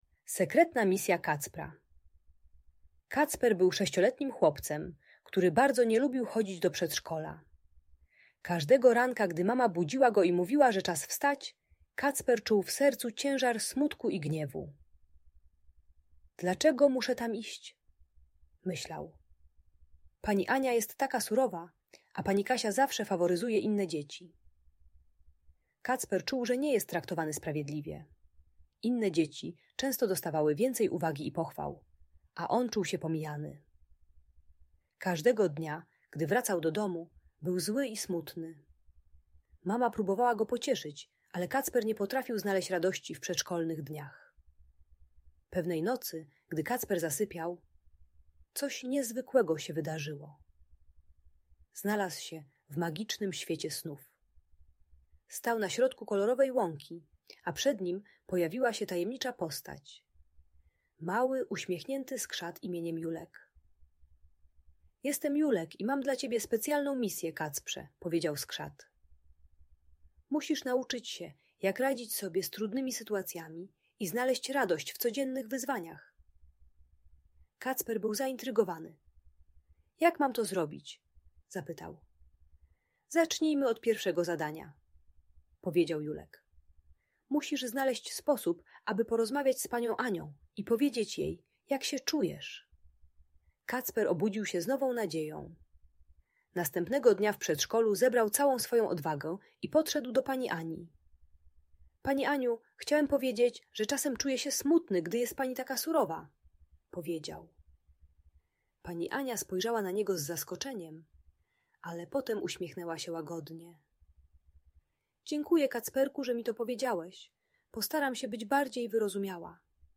Sekretna Misja Kacpra - Audiobajka